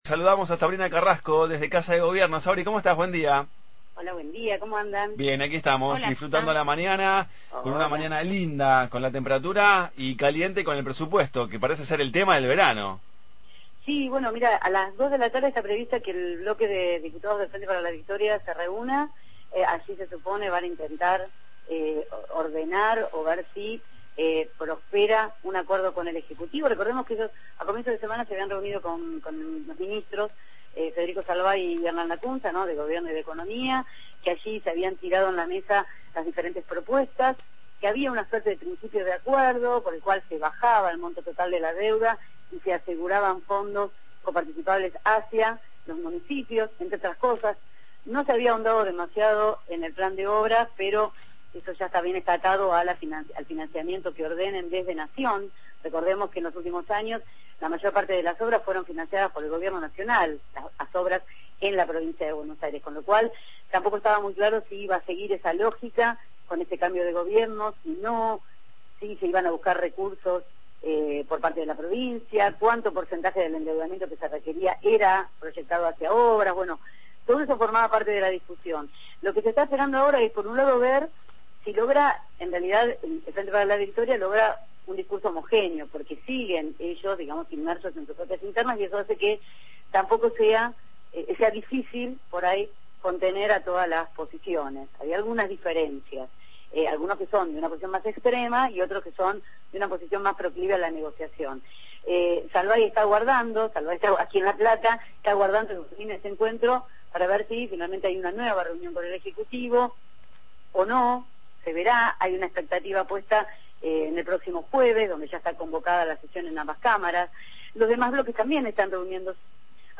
desde Casa de Gobierno provincial, sobre reunión de legisladores del FpV sobre presupuesto y temas varios de provincia